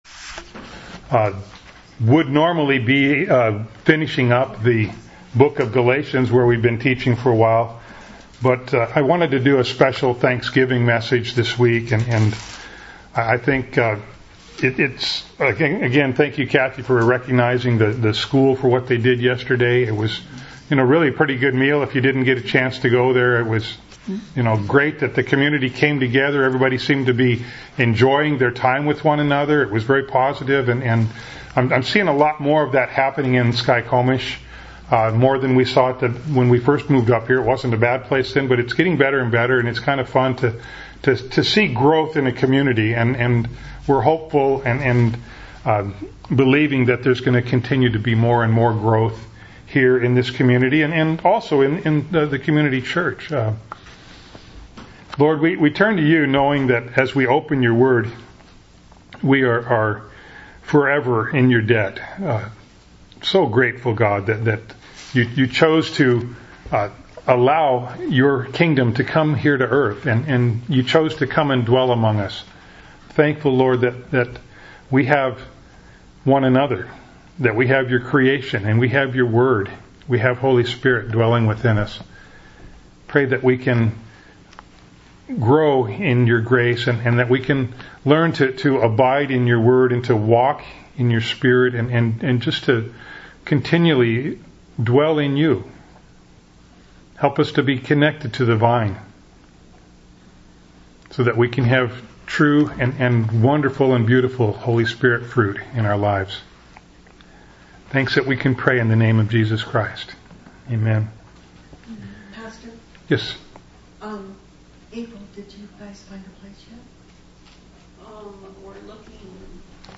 Thanksgiving Message 2014